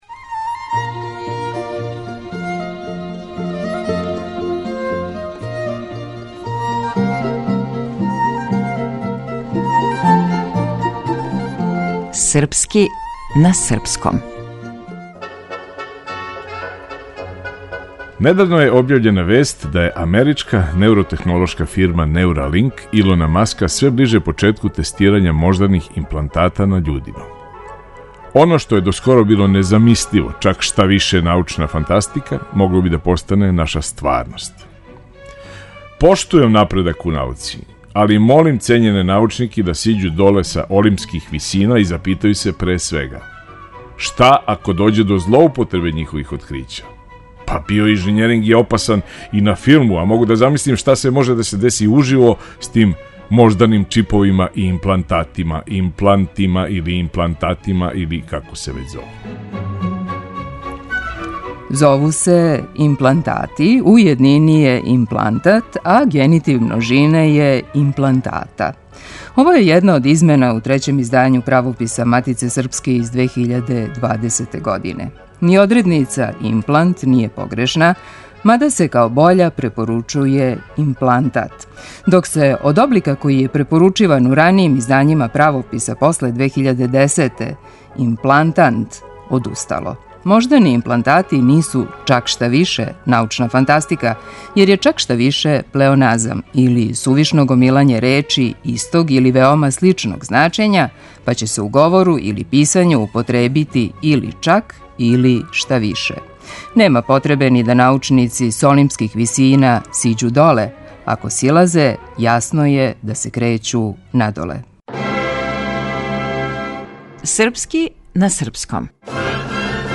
Глумац - Никола Којо